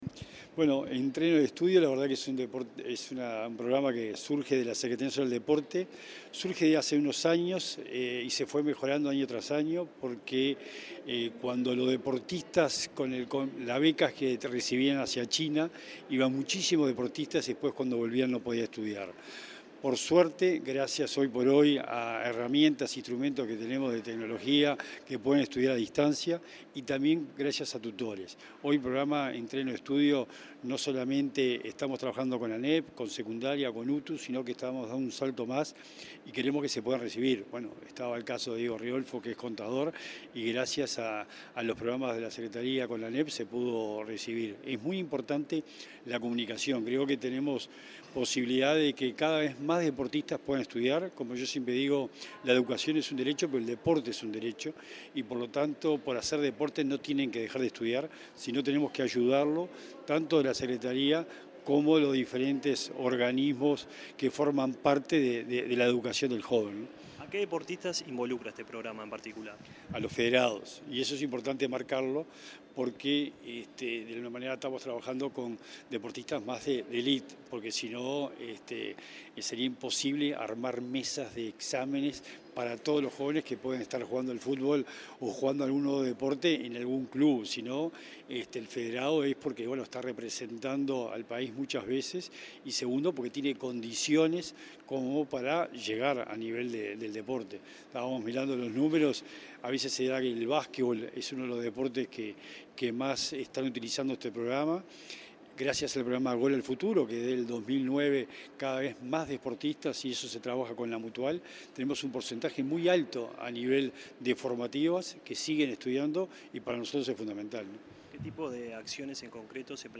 Entrevista al secretario nacional del Deporte, Sebastián Bauzá